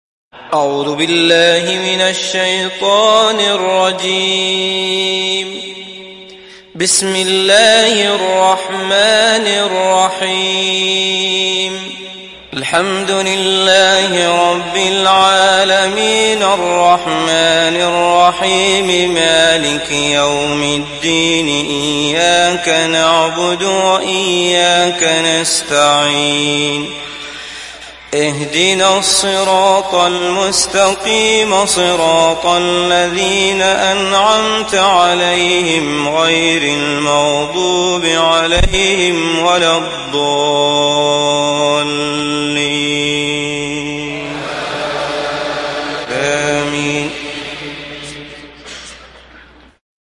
Fatiha Suresi İndir mp3 Abdullah Al Matrood Riwayat Hafs an Asim, Kurani indirin ve mp3 tam doğrudan bağlantılar dinle